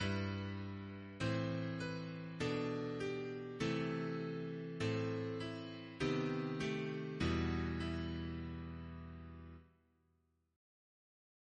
Single chant in E minor Composer
chant in unison Reference psalters